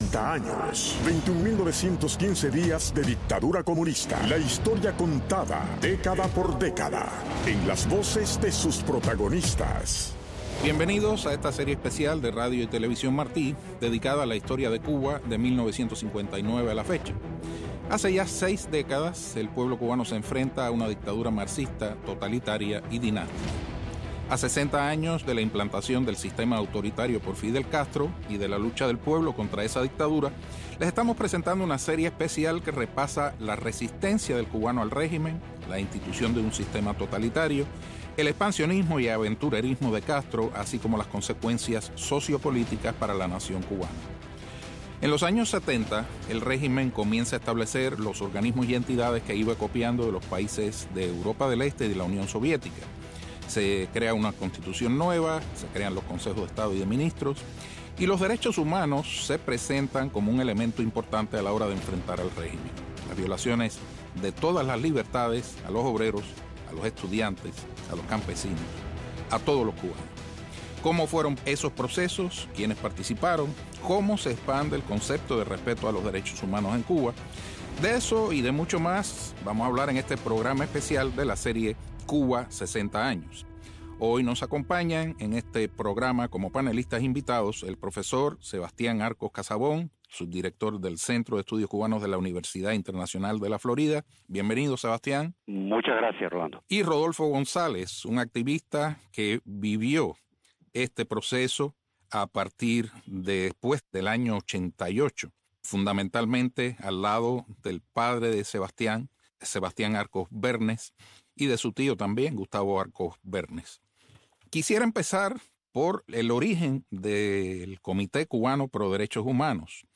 Invitados